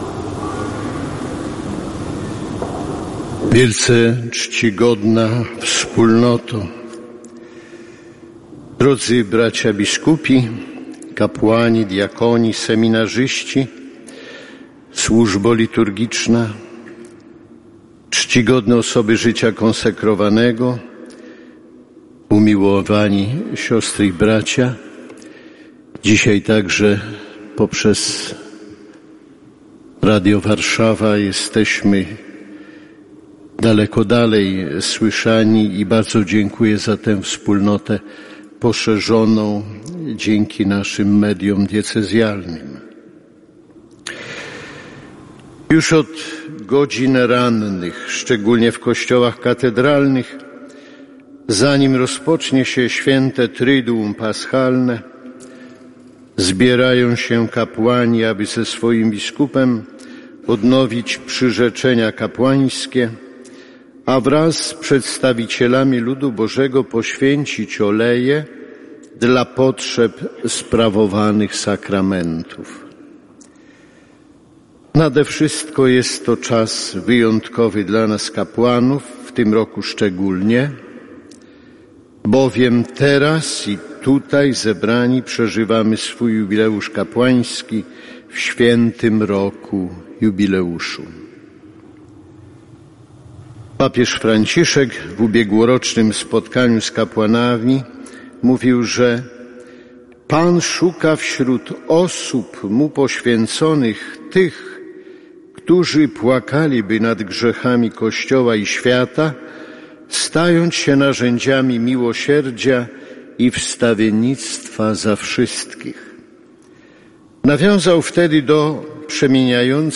Msza Krzyżma w katedrze św. Floriana - Radio Warszawa 106,2 FM
W Wielki Czwartek, w katedrze Św. Floriana na warszawskiej Pradze, odbyła się Msza Krzyżma, podczas której kapłani diecezji warszawsko-praskiej odnowili swoje przyrzeczenia kapłańskie. Liturgii przewodniczył biskup Romuald Kamiński, ordynariusz diecezji, który wygłosił poruszającą homilię na temat roli kapłanów w Kościele.
homilia-Romuald-1.mp3